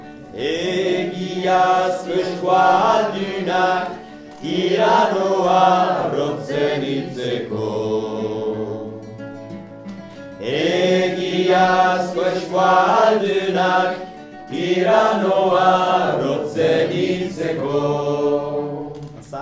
hommes_part3.mp3